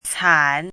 怎么读
cǎn
can3.mp3